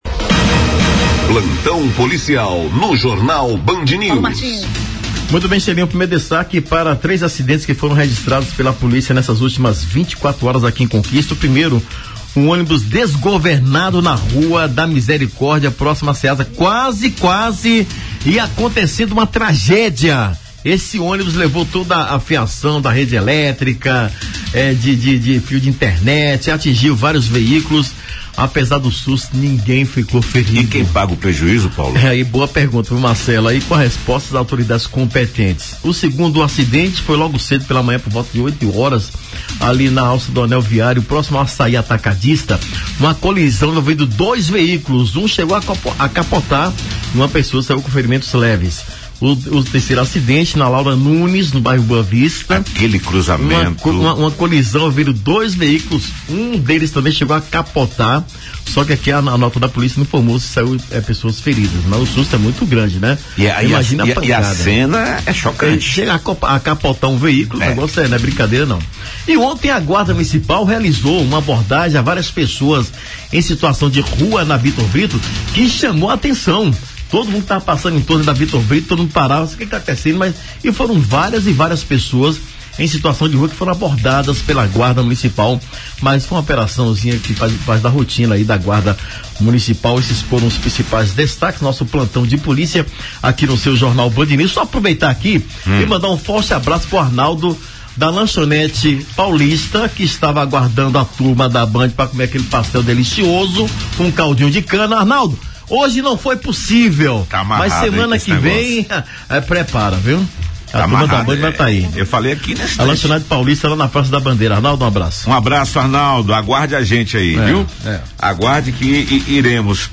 No player abaixo, é possível ouvir os detalhes de três graves acidentes registrados em Vitória da Conquista, incluindo um capotamento de carro e o caso do ônibus desgovernado na Rua da Misericórdia.